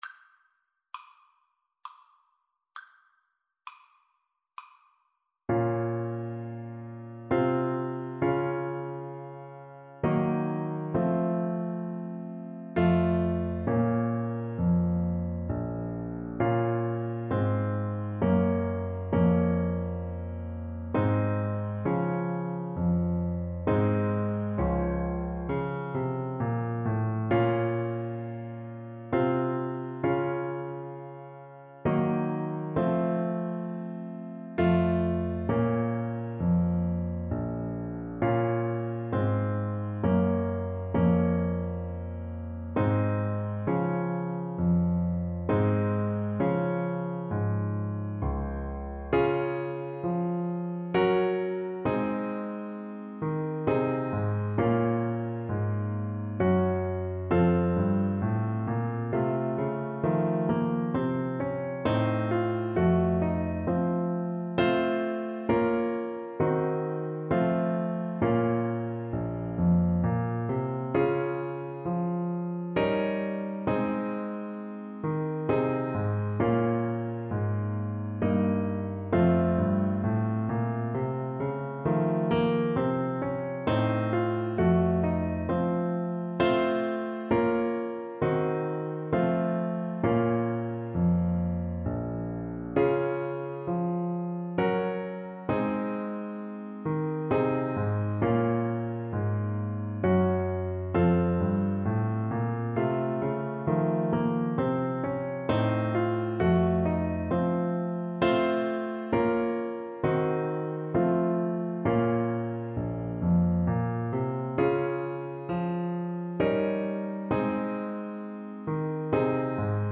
3/4 (View more 3/4 Music)
Classical (View more Classical Trumpet Music)